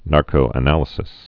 (närkō-ə-nălĭ-sĭs)